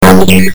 cartoon23.mp3